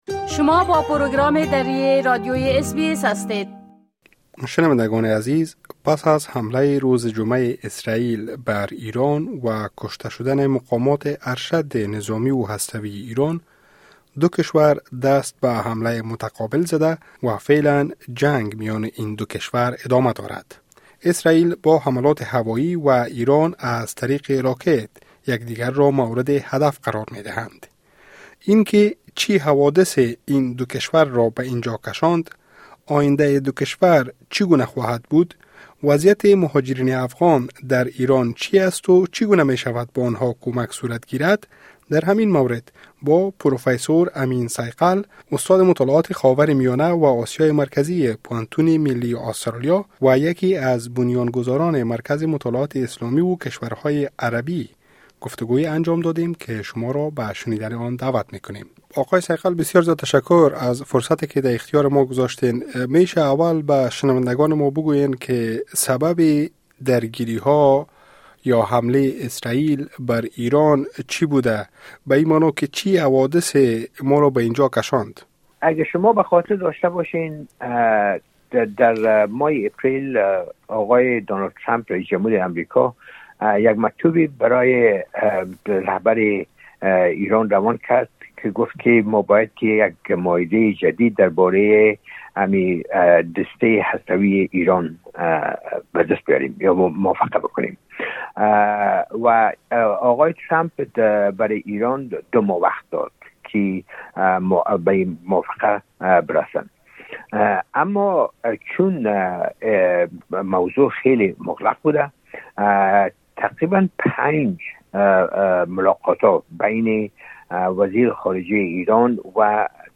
وضعیت مهاجرین افغان در ایران در چنین شرایطی چگونه است و چه راه‌های برای حمایت از آن‌ها وجود دارند؟ در همین زمینه، گفتگوی انجام داده‌ایم با پروفیسور امین صیقل، استاد مطالعات خاورمیانه و آسیای مرکزی در پوهنتون ملی آسترالیا و یکی از بنیان‌گذاران مرکز مطالعات اسلامی و کشورهای عربی. شما را به شنیدن این گفتگو دعوت می‌کنیم.